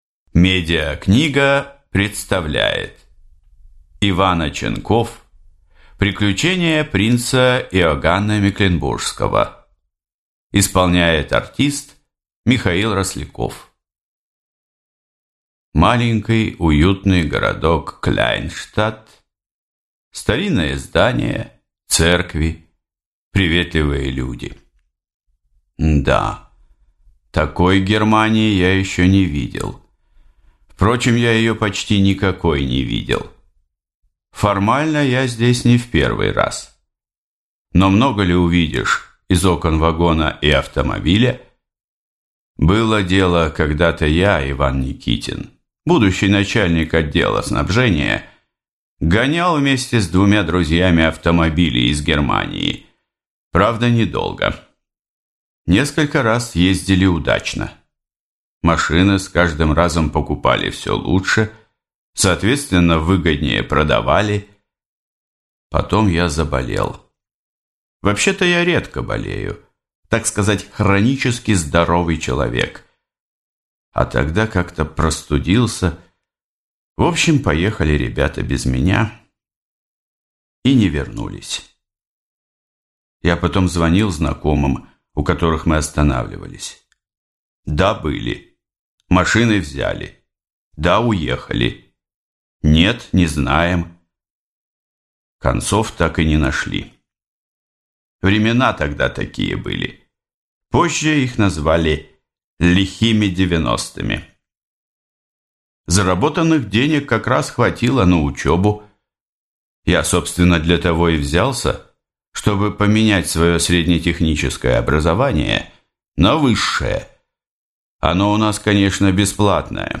Аудиокнига Приключения принца Иоганна Мекленбургского | Библиотека аудиокниг